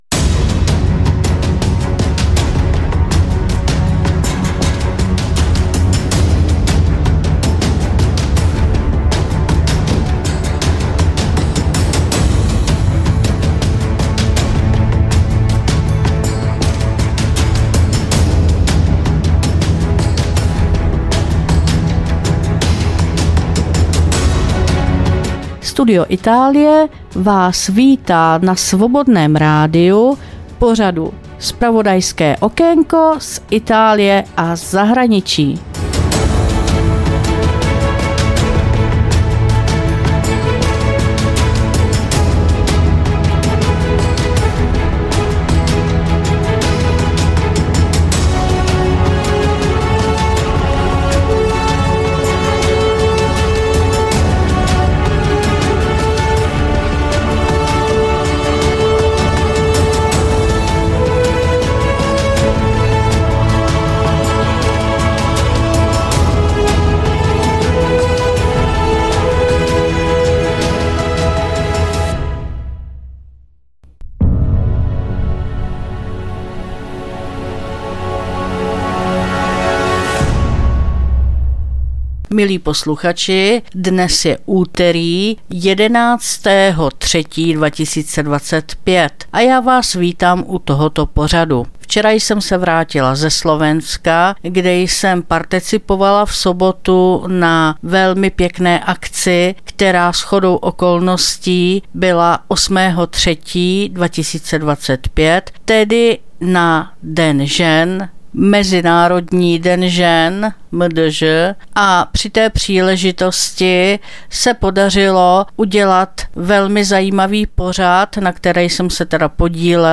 2025-03-11 - Studio Itálie - záznam z pracovního setkání Ministra pro cestovní ruch a sport Rudolfa Huliaka se starosty a zástupci sportovních organizací na MDŽ - 8.3.2025 Stupava